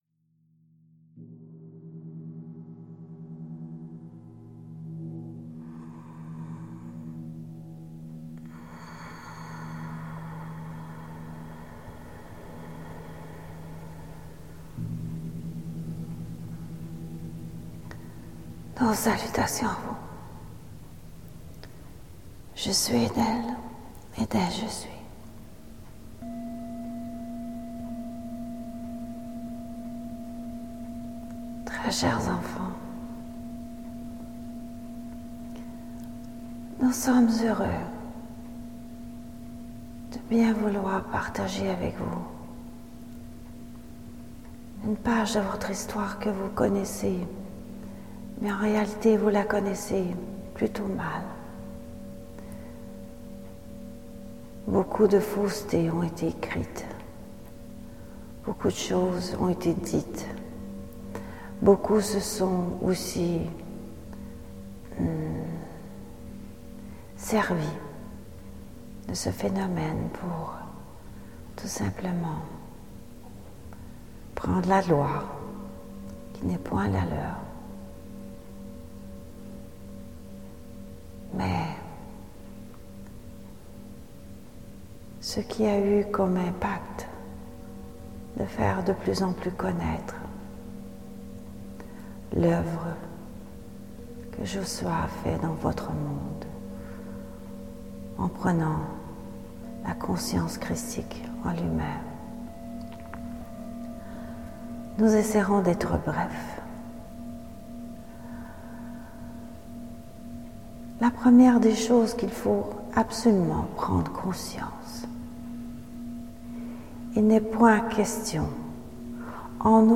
Certaines des respirations que vous pourriez entendre sont celles de l’accompagnant…